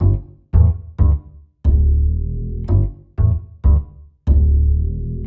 Bass 49.wav